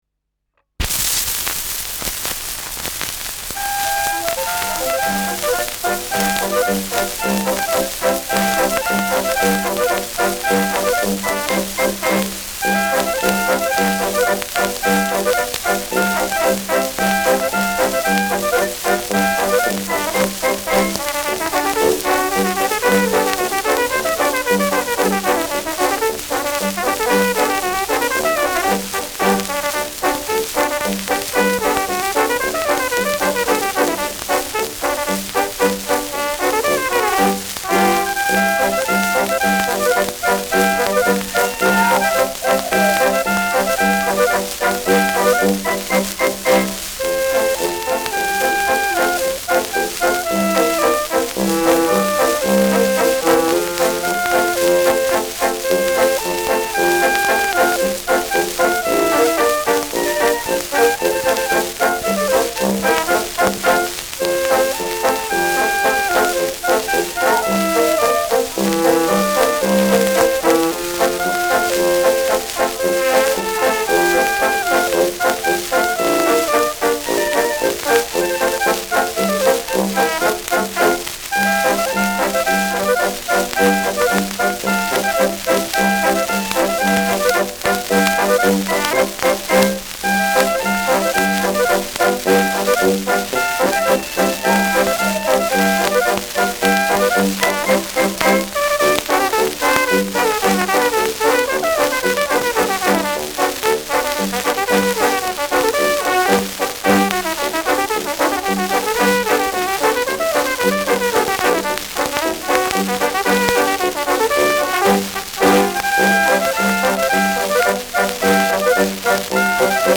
Schellackplatte
präsentes Rauschen : präsentes Knistern : gelegentlich präsentes Nadelgeräusch : abgespielt : leichtes Leiern : Knacken bei 2’30’’
Dachauer Bauernkapelle (Interpretation)
Mit Juchzern.